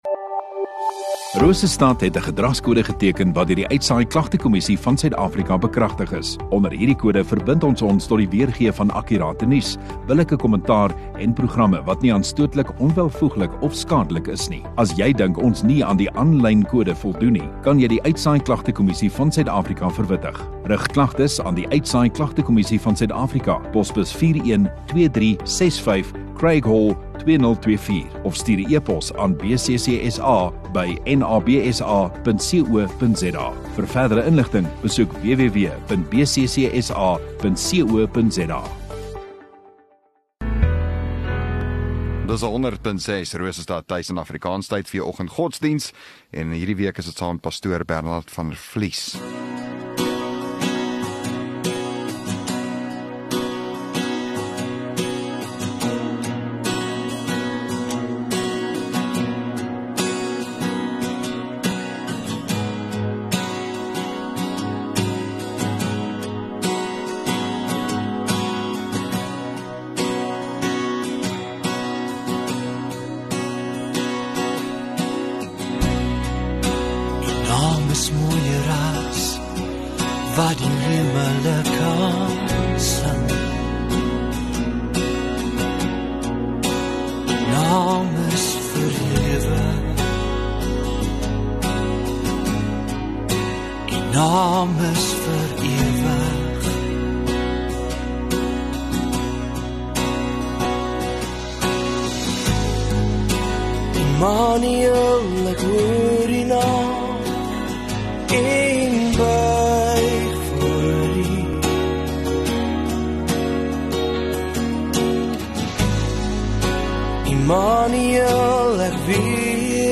25 Apr Vrydag Oggenddiens